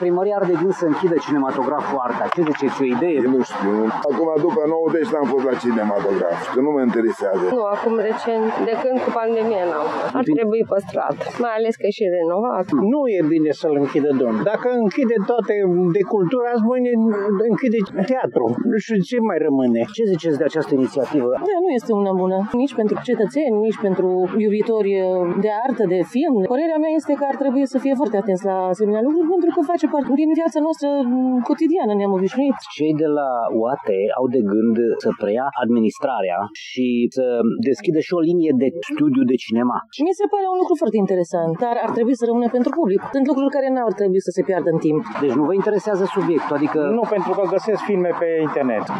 Reacțiile târgumureșenilor sunt diverse, unii nu sunt interesați de subiect, alții cred că este importat pentru oraș să aibă un cinematograf :